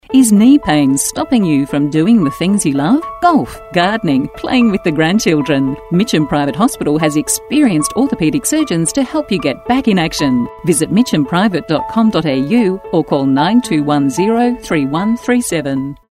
Listen to our radio message
Mitcham-Private-Radio1278-ad-2.mp3